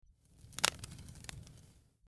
Fire Crackling 02
fire_crackling_02.mp3